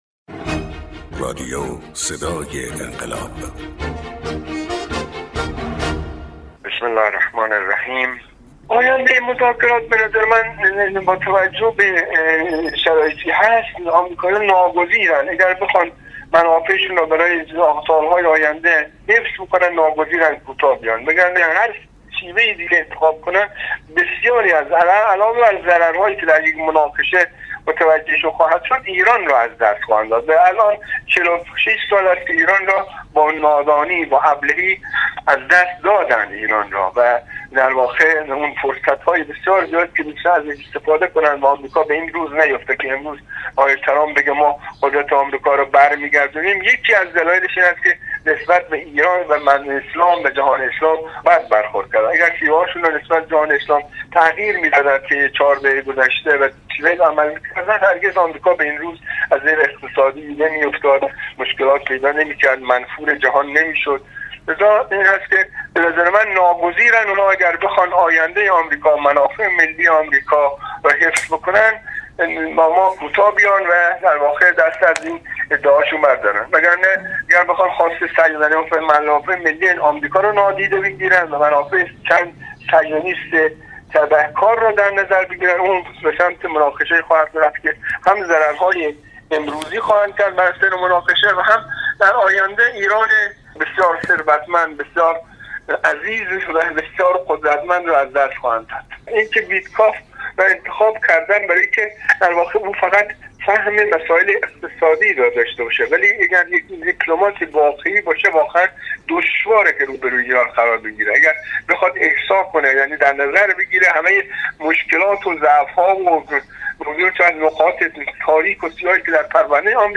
کارشناس برنامه: